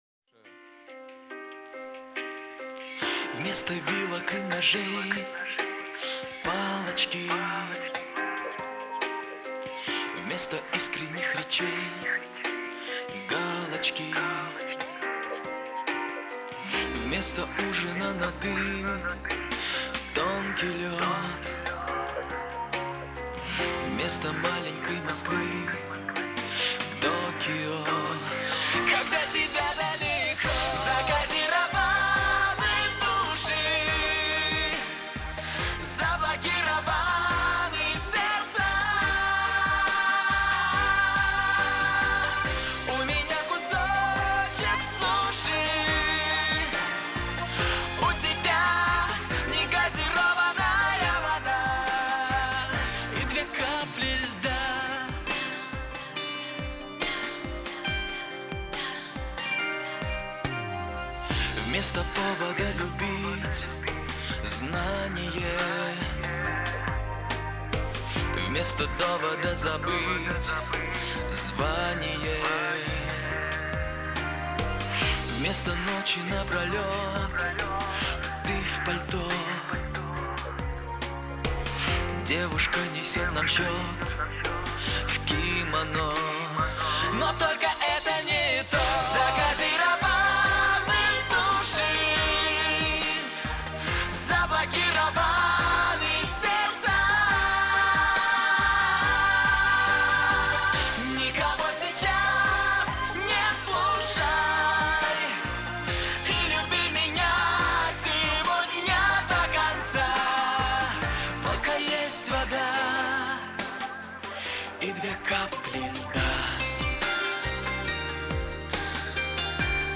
соло